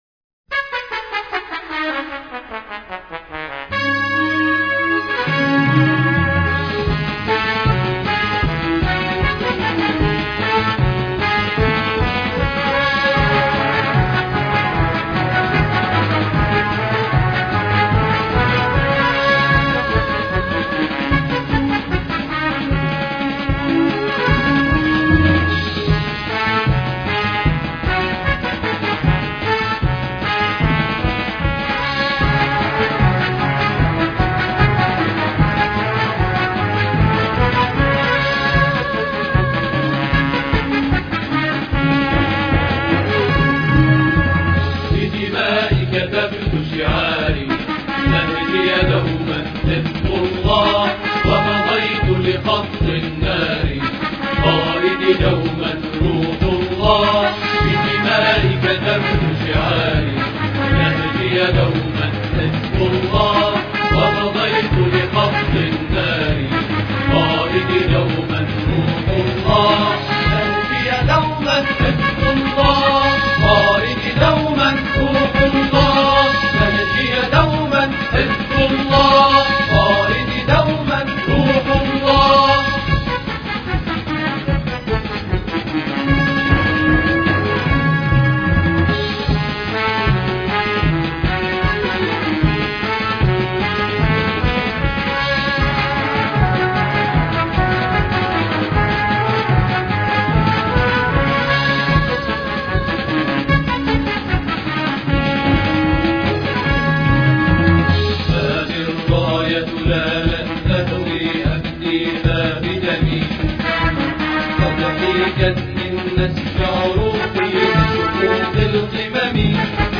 بدمائي كتبت شعاري الإثنين 14 سبتمبر 2009 - 00:00 بتوقيت طهران تنزيل الحماسية شاركوا هذا الخبر مع أصدقائكم ذات صلة الاقصى شد الرحلة أيها السائل عني من أنا..